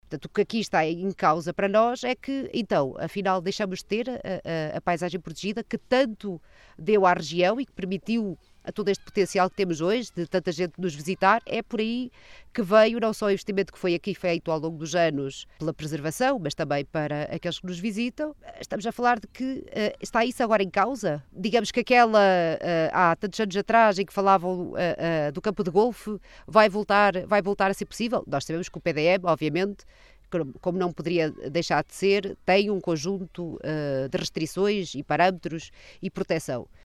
As preocupações foram explanadas no passado sábado, no âmbito de uma conferência de imprensa, na Praia da Ribeira, na albufeira do Azibo, no âmbito do Roteiro do Ambiente, que promoveu pelo distrito de Bragança.